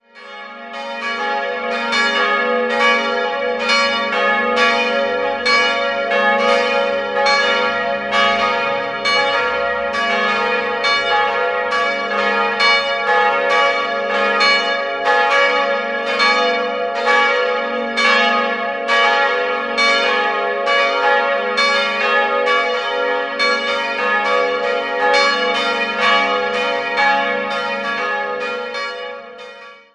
Sie wurde 1886/1887 im romanisch-lombardischen Stil als dreischiffige Anlage mit einem hoch aufragenden, pyramidenbekrönten Turm errichtet. Im protestantisch-schlichten Inneren sind besonders die schönen Glasfenster in der Apsis und der Taufkapelle erwähnenswert. 3-stimmiges Gloria-Geläute: as'-b'-des'' Die Glocken wurden 1960 von der Gießerei Bachert in Karlsruhe gegossen.